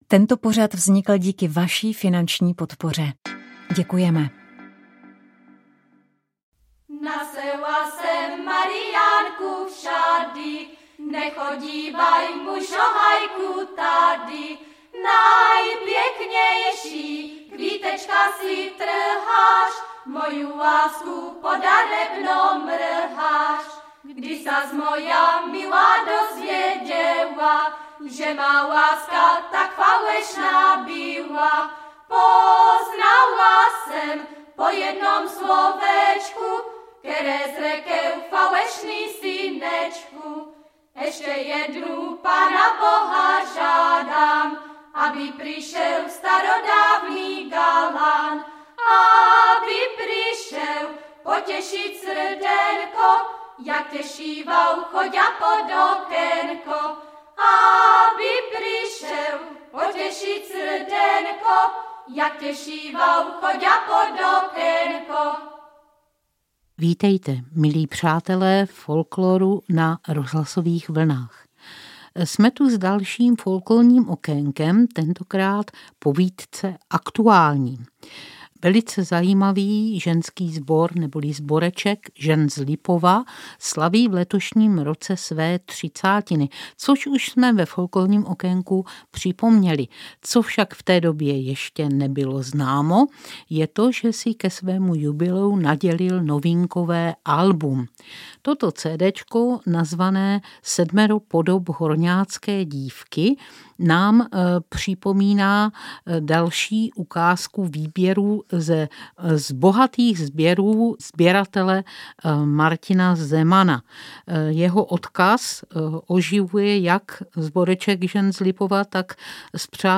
Pořad je zároveň pozvánkou na připravovanou výstavu Kroj jak malovaný na zámku v Bučovicích. Rozhovor doprovodí výběr ze svatebních písní různých národopisných regionů.